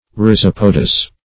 Rhizopodous \Rhi*zop"o*dous\, a.
rhizopodous.mp3